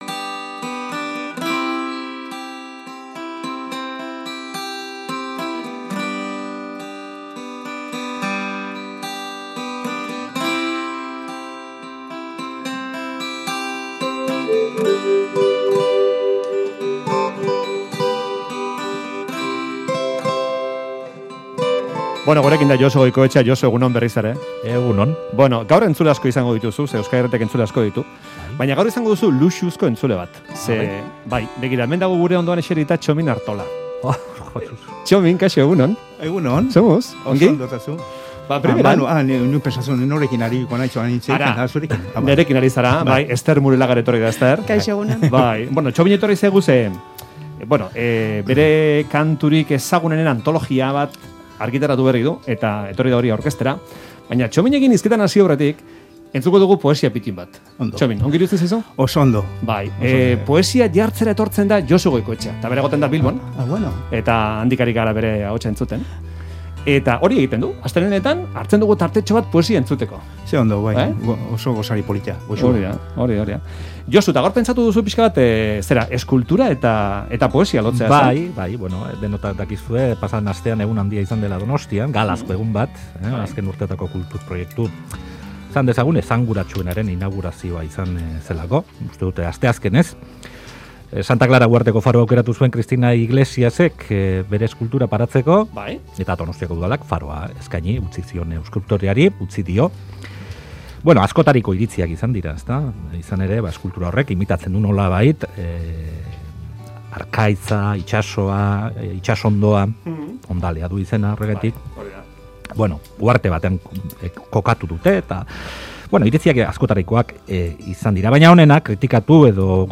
Gabriel Arestik Jorge Oteizari eskainitako olerkia irakurri du